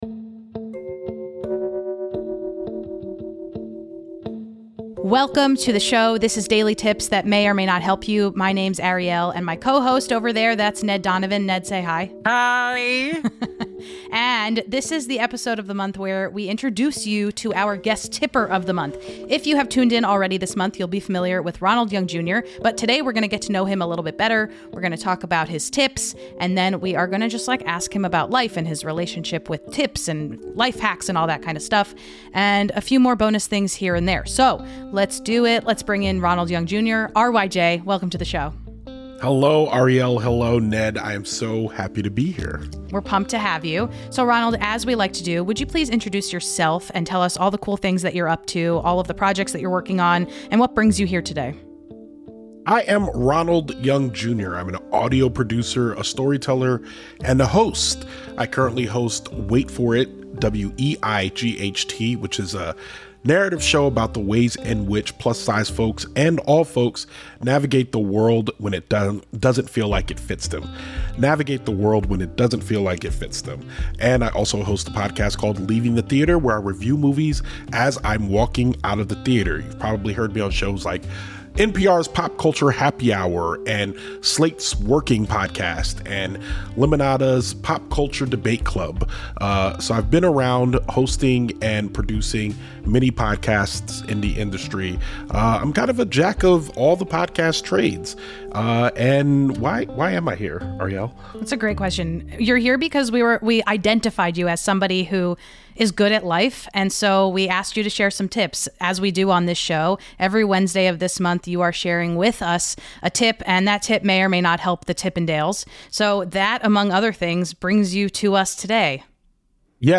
In this month’s guest tipper interview